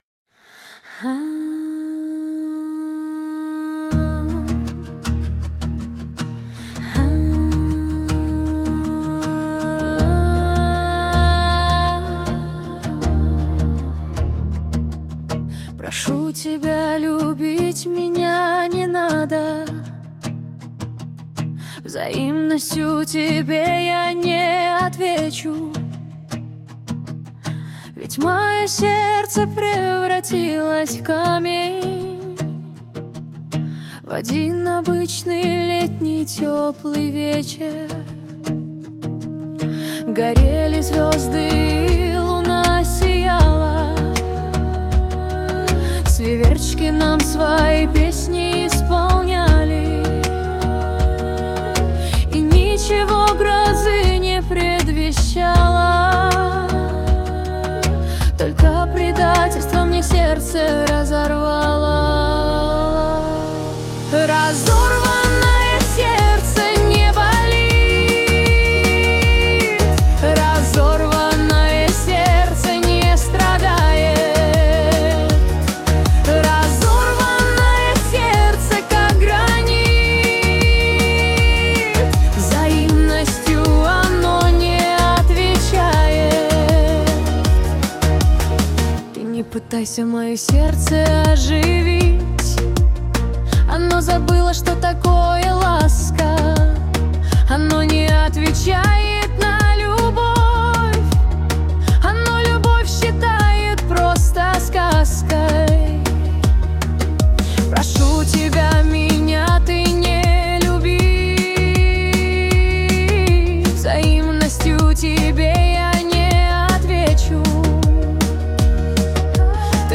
Жанр: Регги